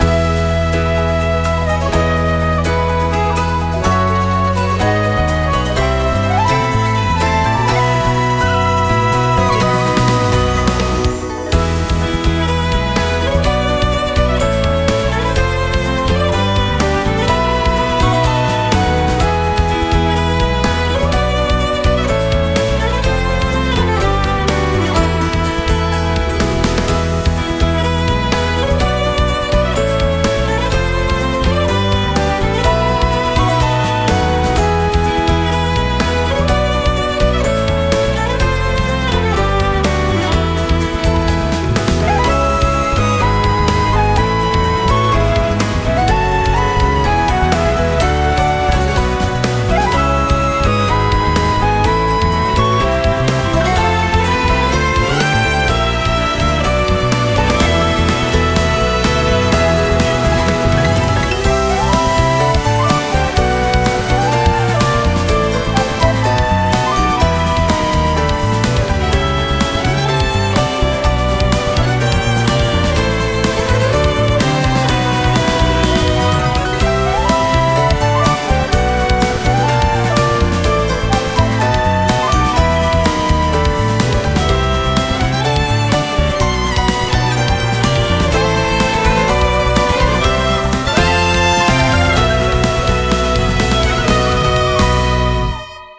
🎻ＢＧＭをご用意しました。